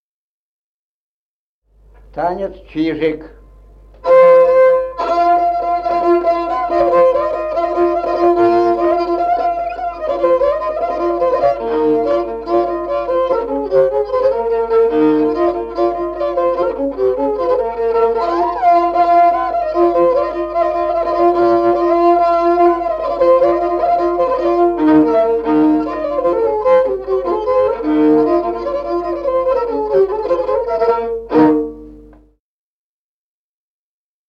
Музыкальный фольклор села Мишковка «Чижик», репертуар скрипача.